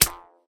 snap.mp3